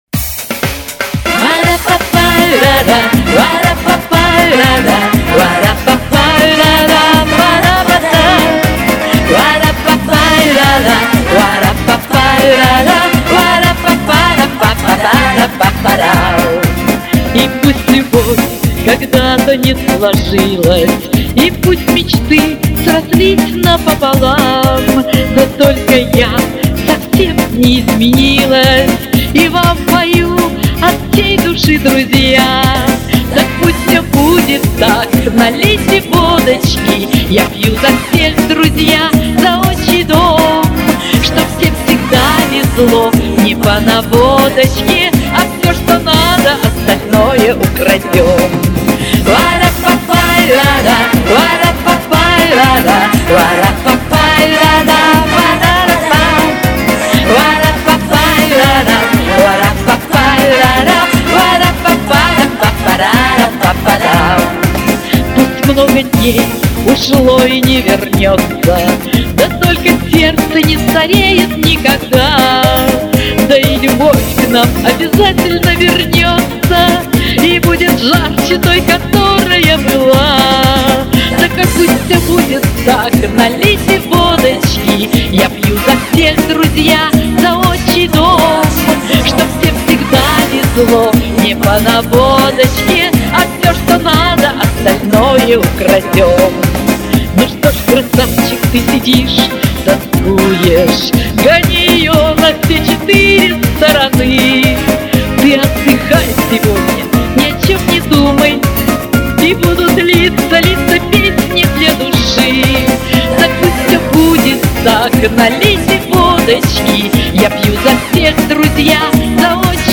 Оба с азартом, оба искренние, оба слушаются достойно!